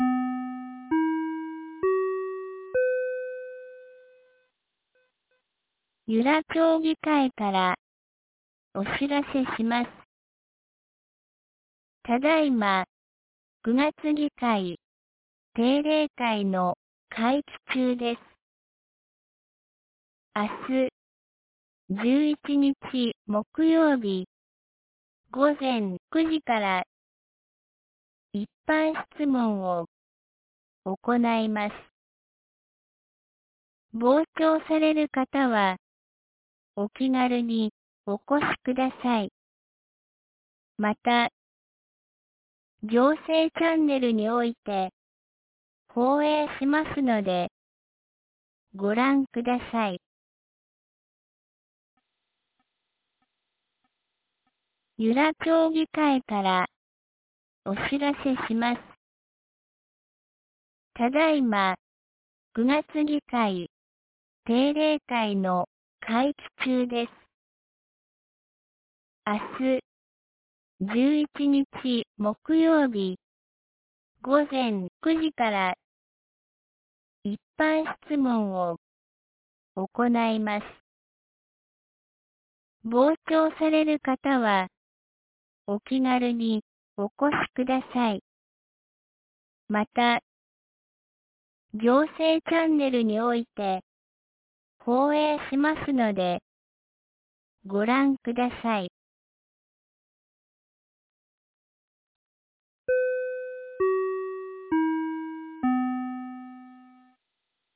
2025年09月10日 12時22分に、由良町から全地区へ放送がありました。